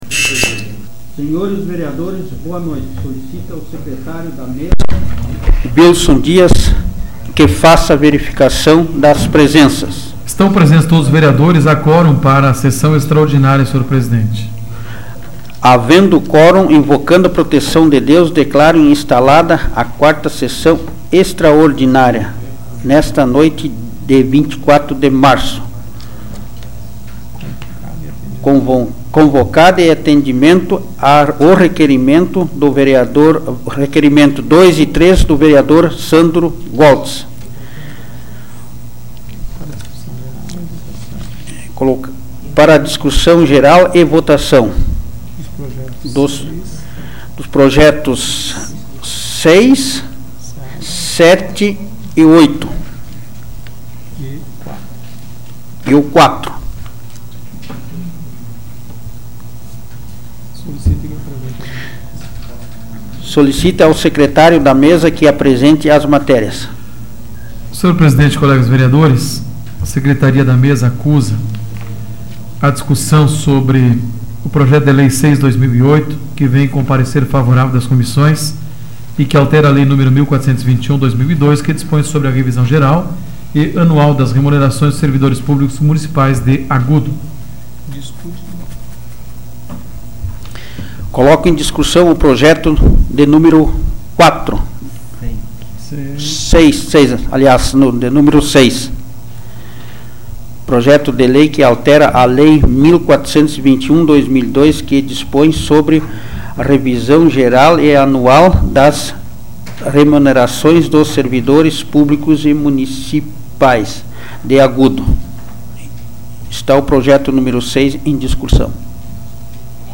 Áudio da 48ª Sessão Plenária Extraordinária da 12ª Legislatura, de 24 de março de 2008